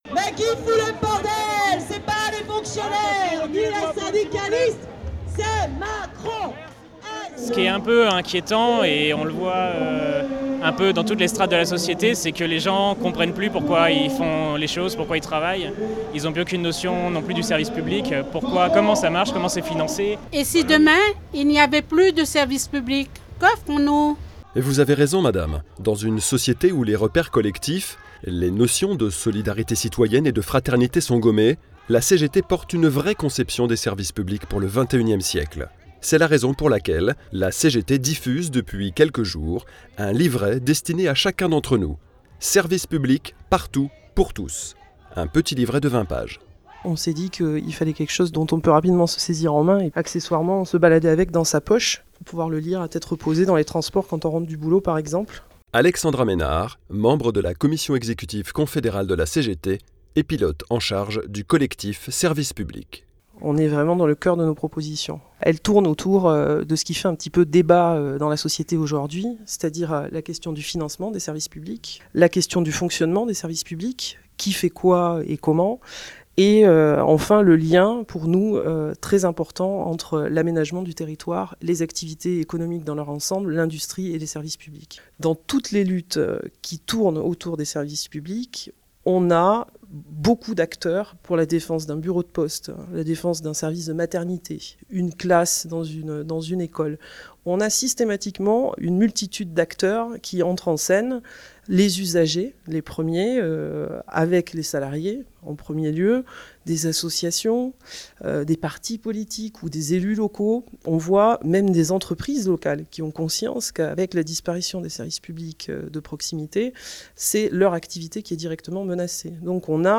Radio Un nouveau livret sur la vision des services publics par la CGT !